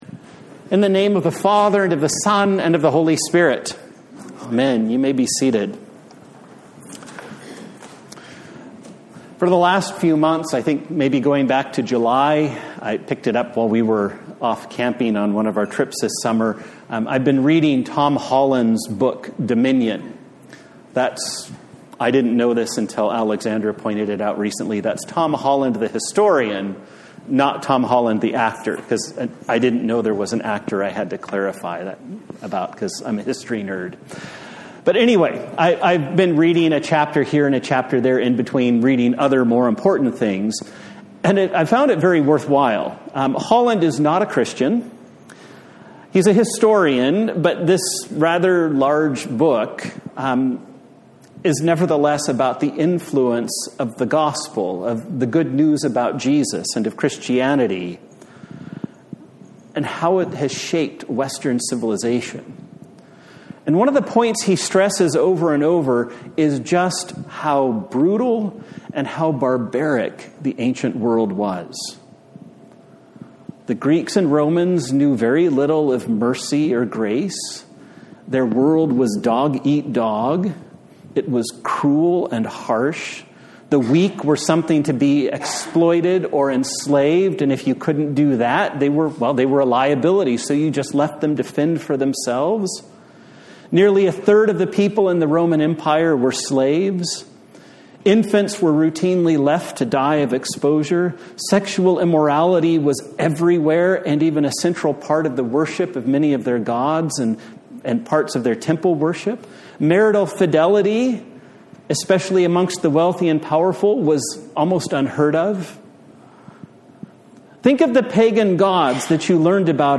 A Sermon for the Fourth Sunday in Advent
Service Type: Sunday Morning